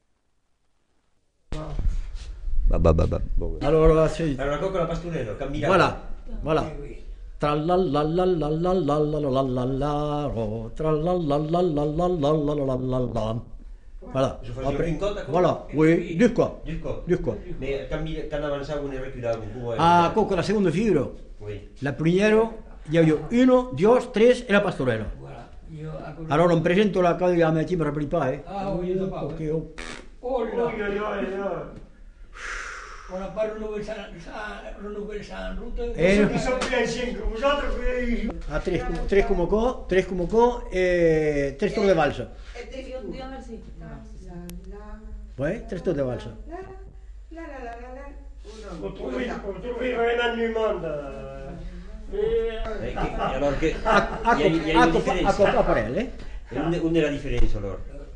Aire culturelle : Agenais
Genre : chant
Effectif : 1
Type de voix : voix d'homme
Production du son : fredonné
Danse : quadrille